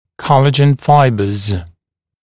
[‘kɔləʤən ‘faɪbəz][‘колэджэн ‘файбэз]коллагеновые волокна